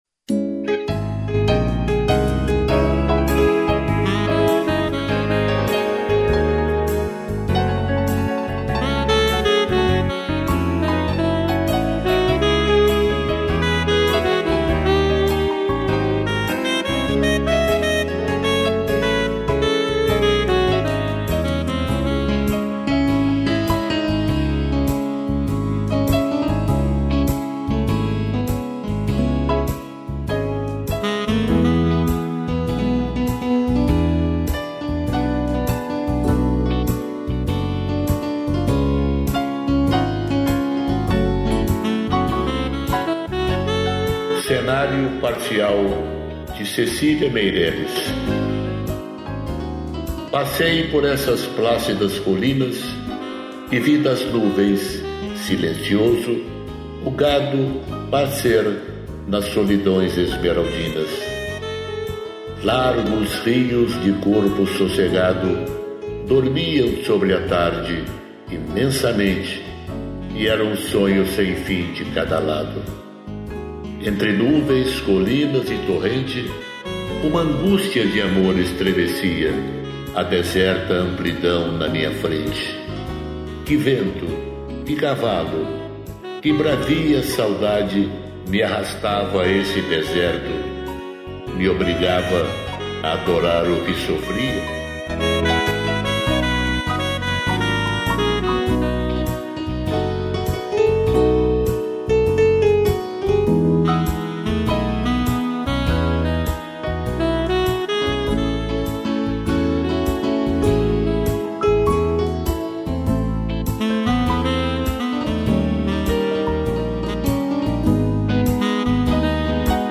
piano e sax